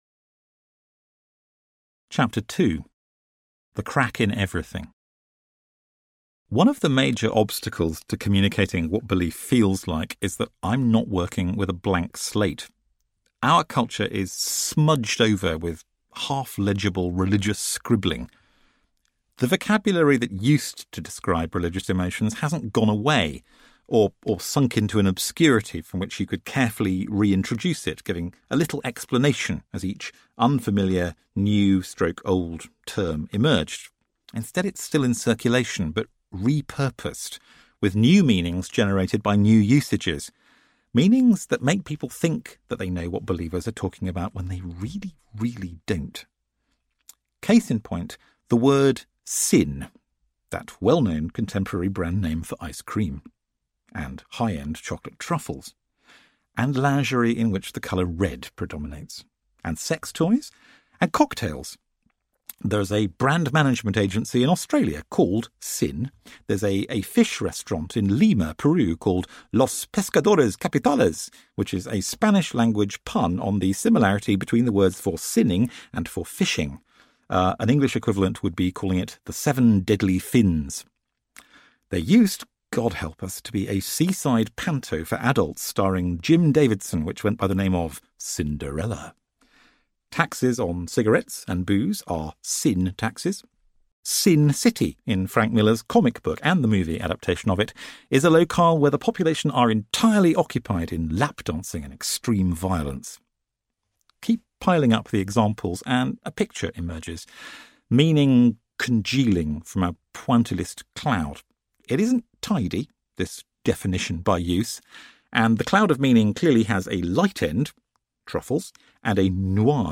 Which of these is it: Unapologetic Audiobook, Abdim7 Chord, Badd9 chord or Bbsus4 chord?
Unapologetic Audiobook